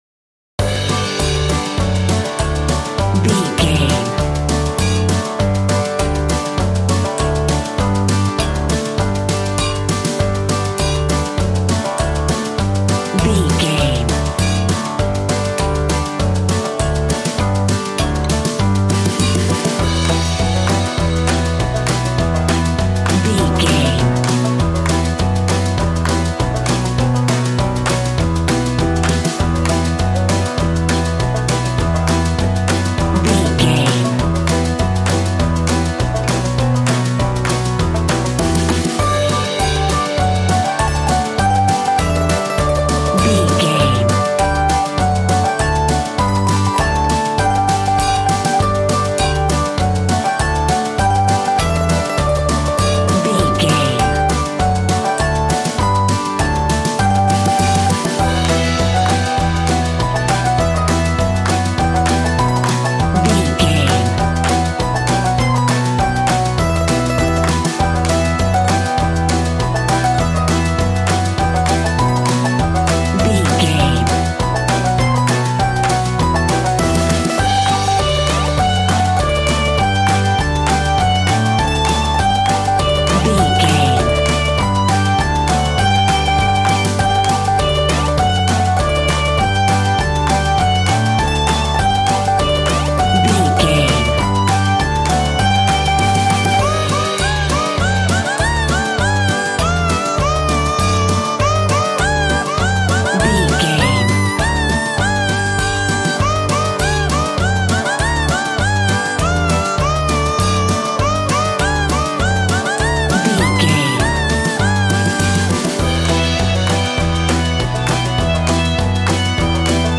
Ionian/Major
cheerful/happy
bouncy
electric piano
electric guitar
drum machine